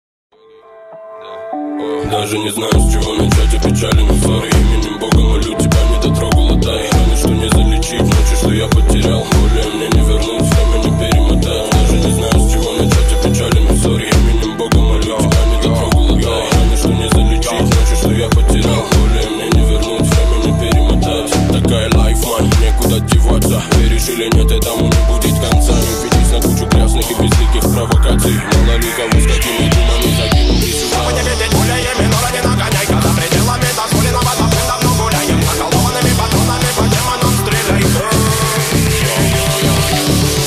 ритмичные
громкие
Club House
Bass